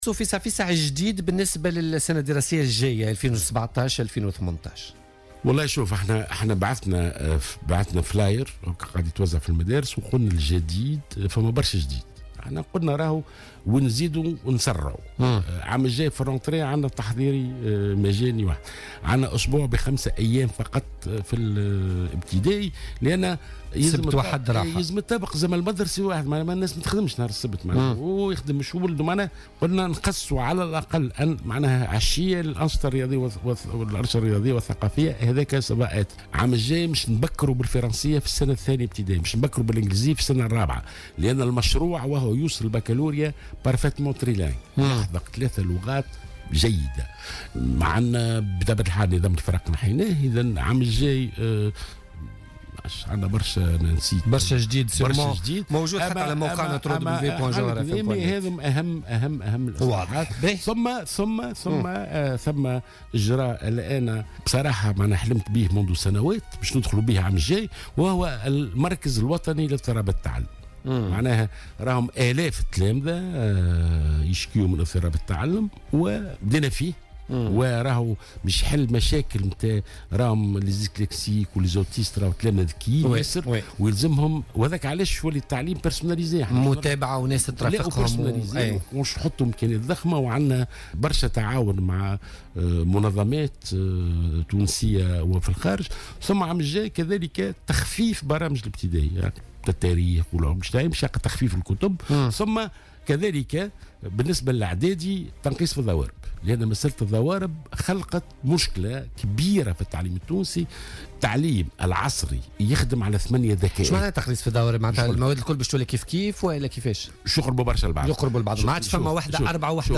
وقال ضيف "بوليتيكا" إن هذه الاجراءات ستشمل عطلتي الشتاء والربيع.